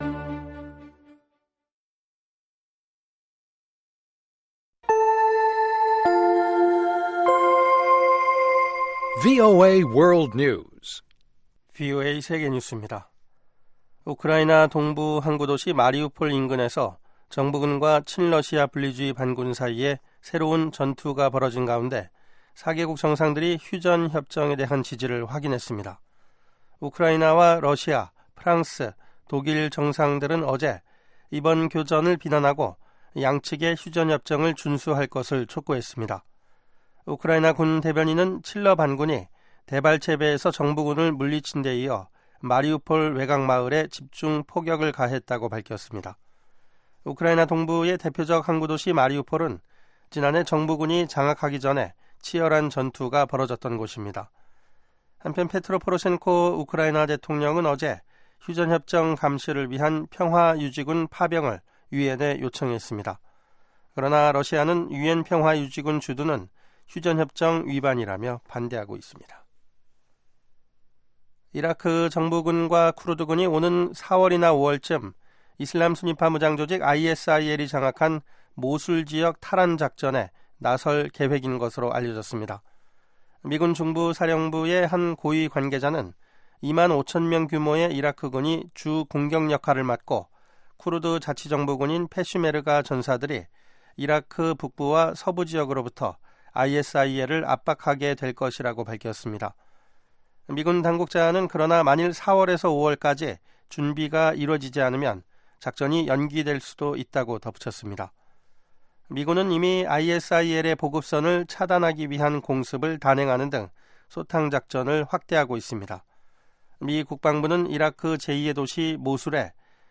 VOA 한국어 방송의 간판 뉴스 프로그램 '뉴스 투데이' 2부입니다. 한반도 시간 매일 오후 9시부터 10시까지 방송됩니다.